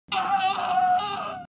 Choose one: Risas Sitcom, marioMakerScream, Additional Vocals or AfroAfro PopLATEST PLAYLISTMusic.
marioMakerScream